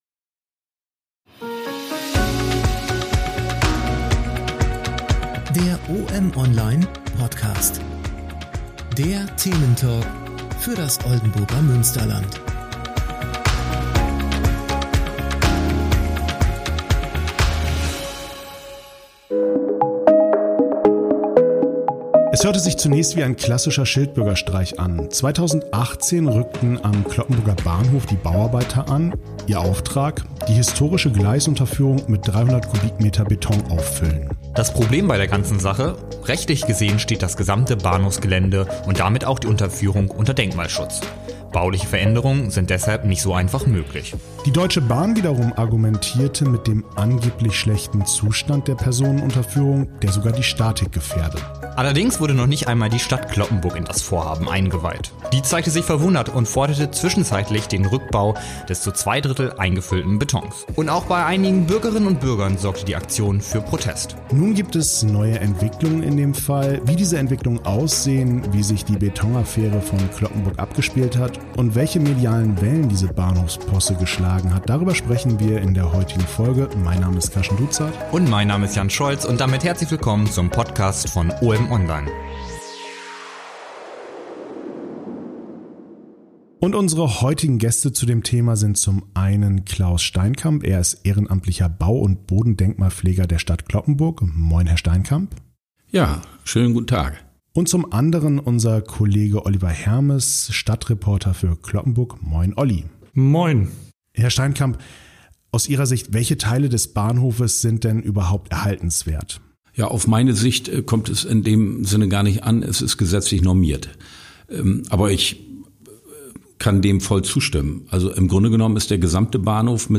Über diese sprechen die Moderatoren